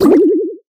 sfx_hit.mp3